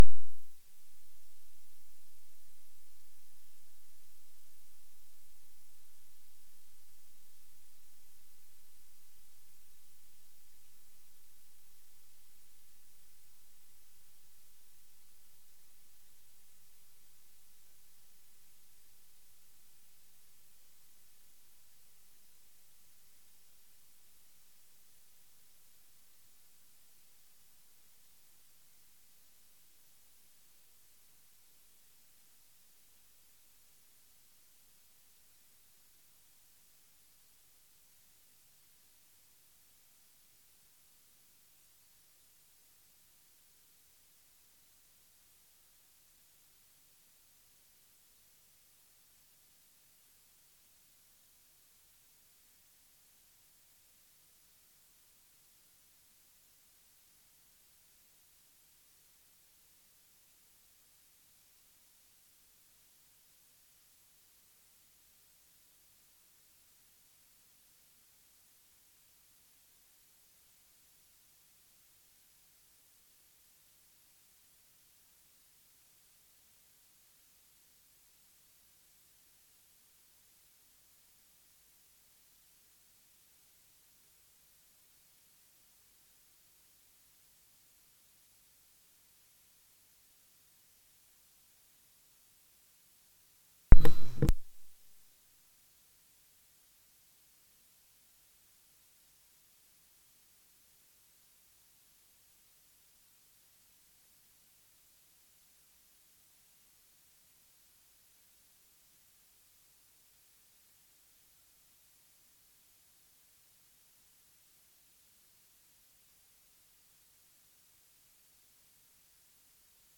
Committee Cabinet Meeting Date 17-06-24 Start Time 18.30 End Time 18:50 Meeting Venue Coltman VC Room, Town Hall, Burton upon Trent Please be aware that not all Council meetings are live streamed.
Meeting Recording 240617.mp3 ( MP3 , 23.17MB )